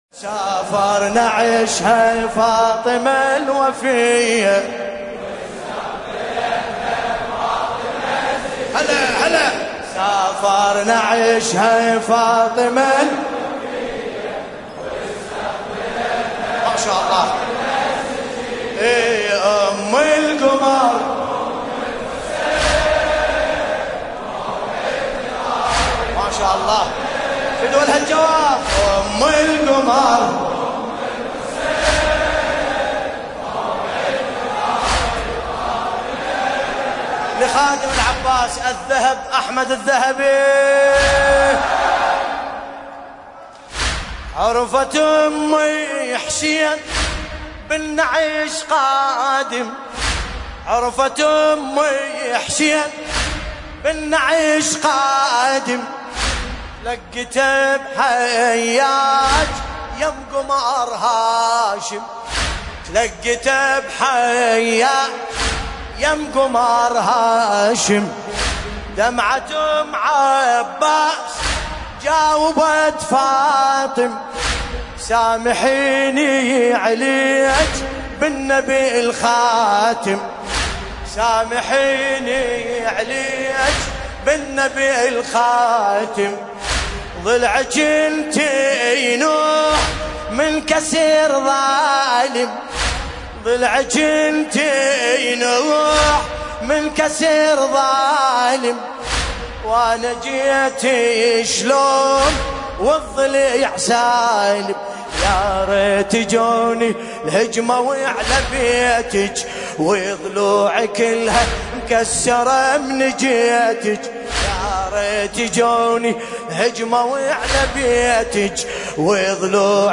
ملف صوتی سافر نعشها بصوت باسم الكربلائي
الرادود : الحاج ملا بلاسم الكربلائي المناسبة : ليلة 13 جمادى الاخره 1441 ه